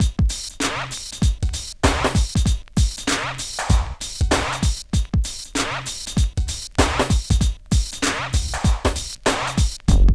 112 DRM LP-R.wav